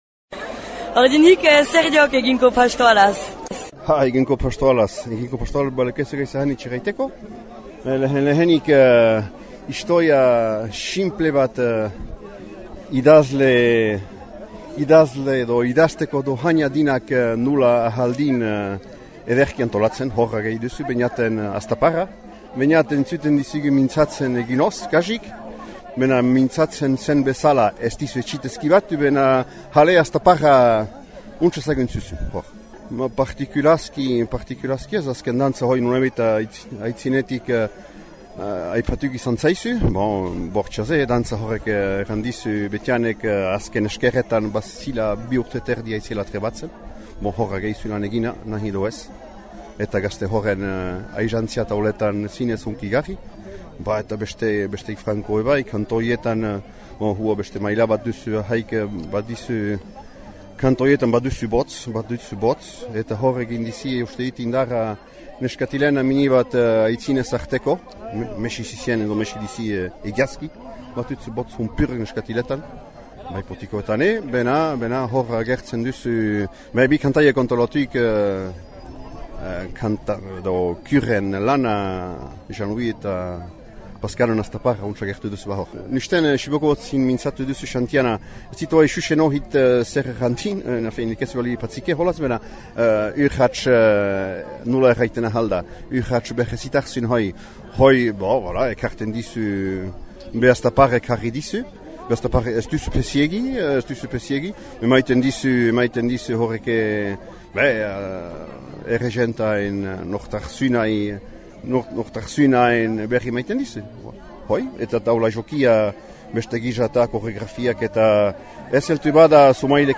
Igandean, 2000 bat jente Gamere Zihigarat hüllantü dira Liguetx Koblakariak pastoraleko lehen emanaldiarentako.